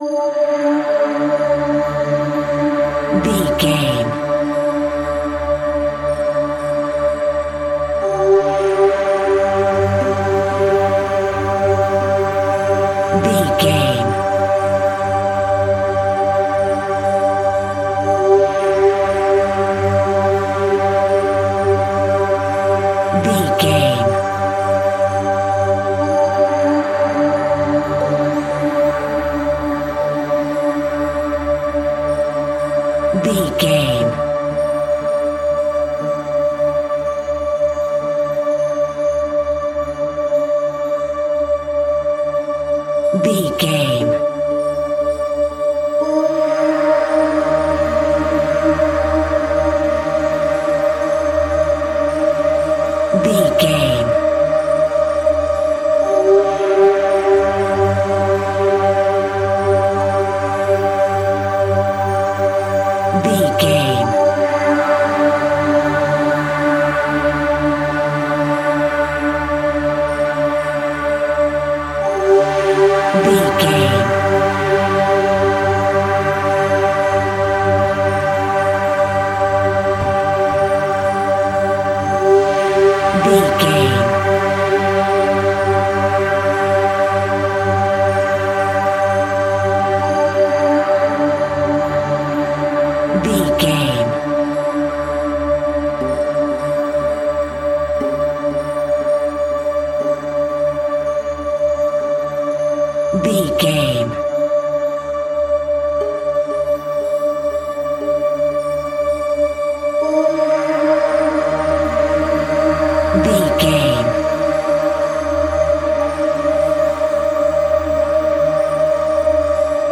In-crescendo
Thriller
Aeolian/Minor
tension
ominous
haunting
Synth Pads
Synth Strings
synth bass